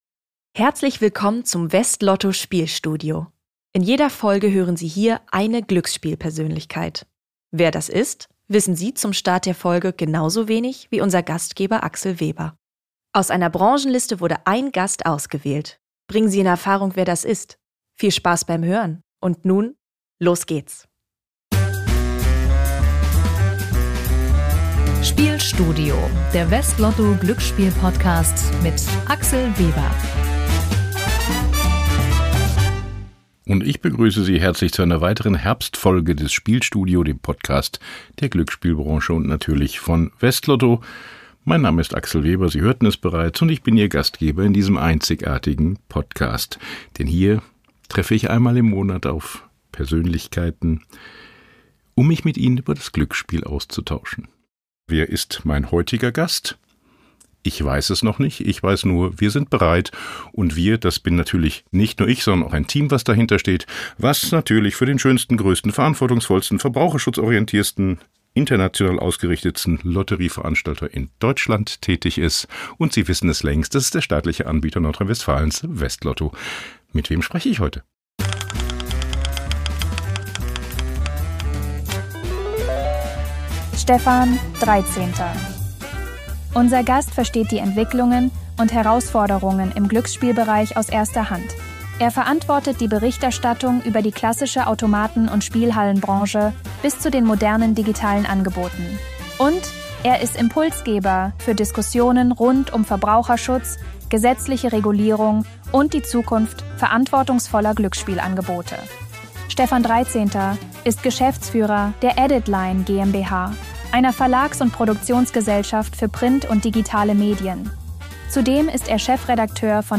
Ein Gespräch über Wandel zwischen Tradition und digitaler Transformation, über Verantwortung und die Suche nach klaren Antworten in bewegten Zeiten.
Hinweis: Diese Folge enthält einen Zuspieler, der von einer KI-Stimme gesprochen wird.